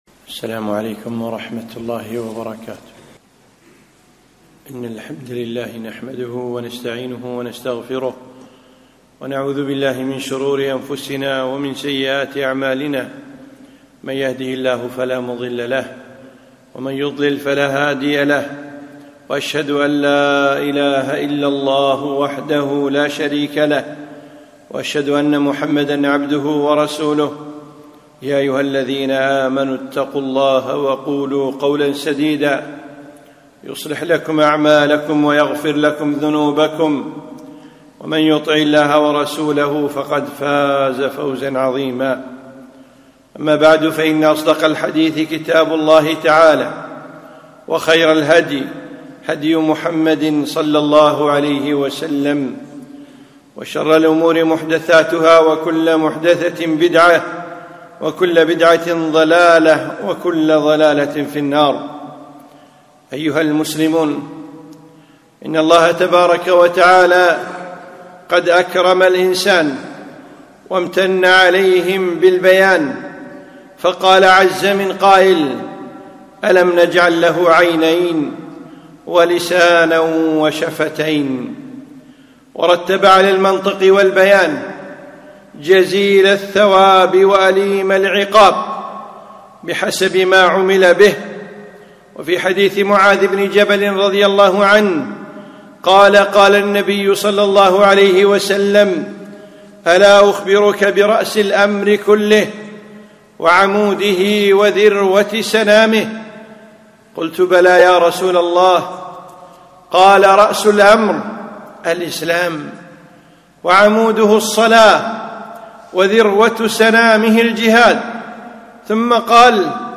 خطبة - صون اللسان